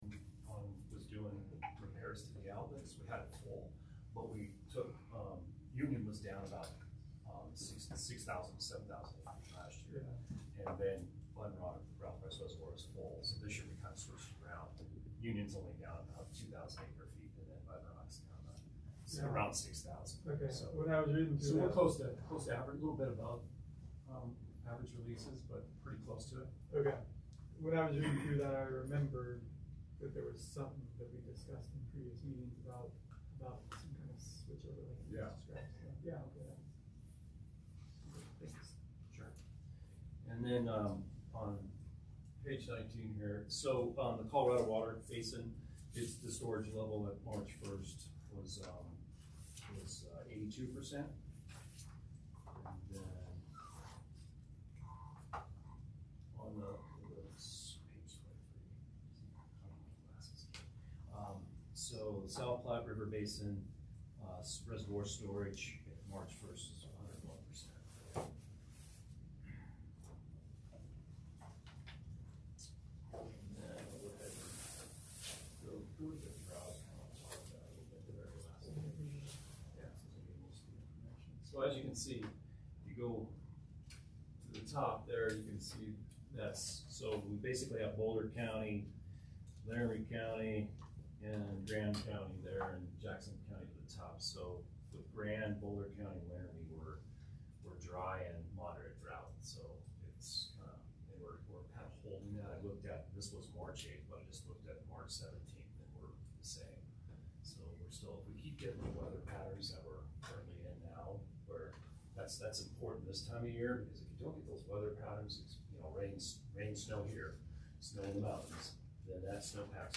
Water Board Meeting - March 21, 2022
The Longmont Water Board Meeting recorded on March 21, 2022